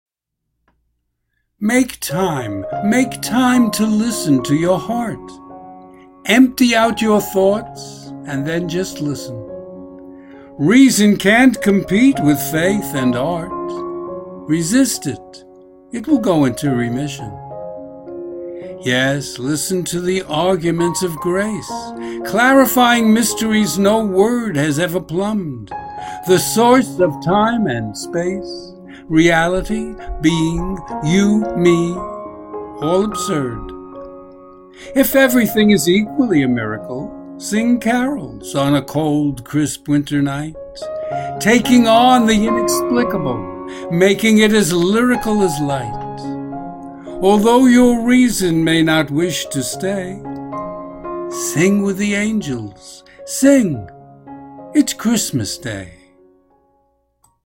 Audio and Video Music: